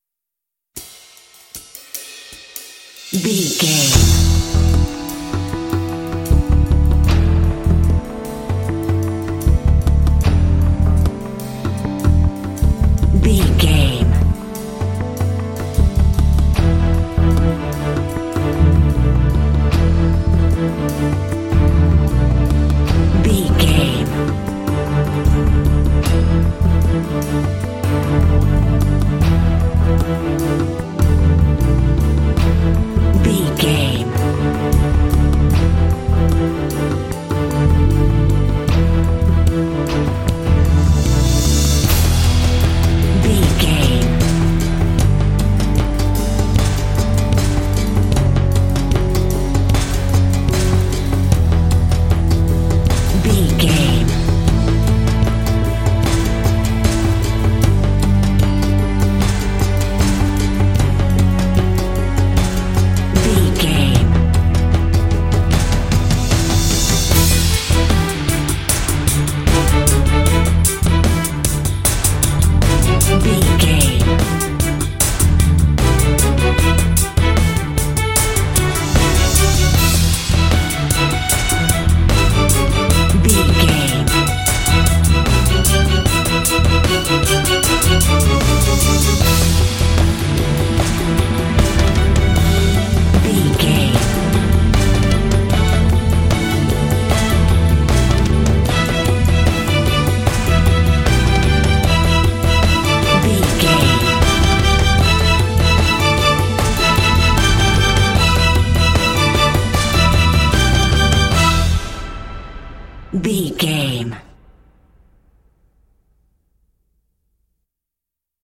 Epic / Action
Fast paced
Aeolian/Minor
dramatic
foreboding
suspense
strings
drums
bass guitar
orchestral
film score
symphonic rock